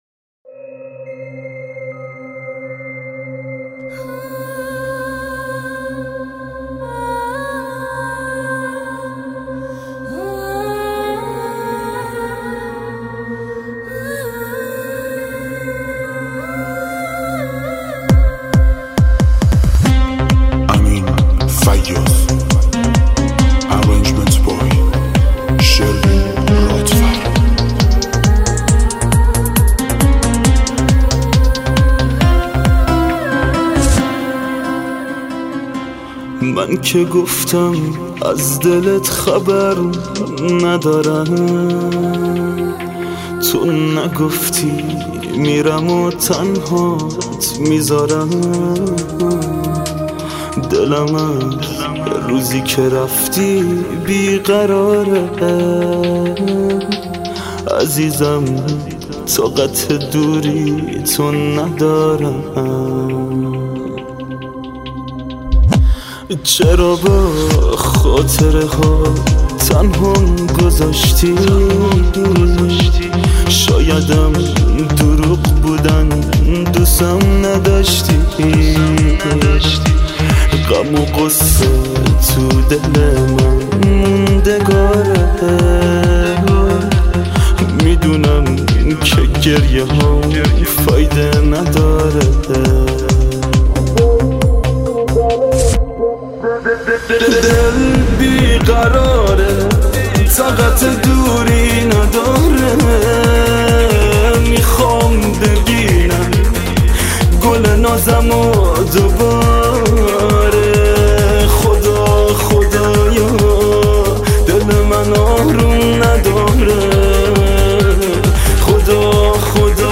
آهنگ غمگین